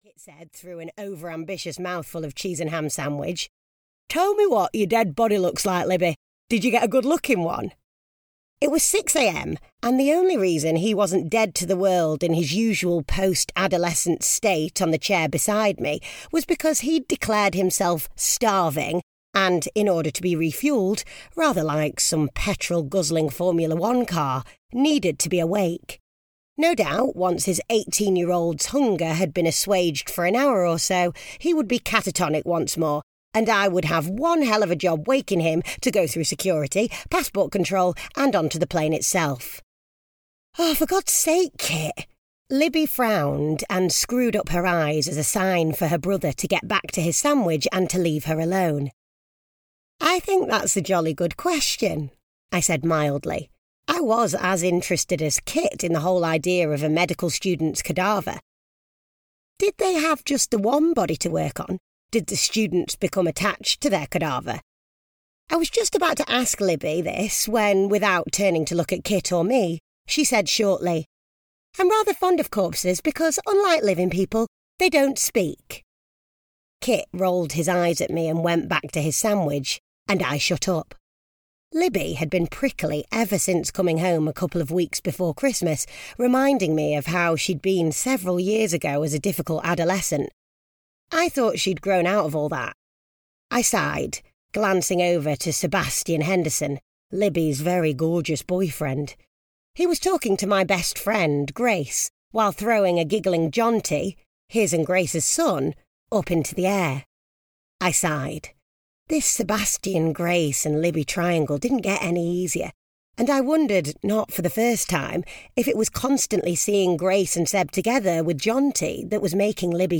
Audio knihaAn Off-Piste Christmas (EN)
Ukázka z knihy